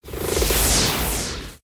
archer_skill_revovaristar_02_load.ogg